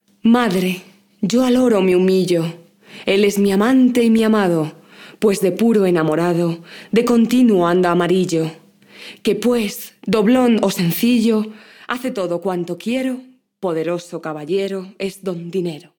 poema
locución
mujer
Sonidos: Voz humana
Sonidos: Textos literarios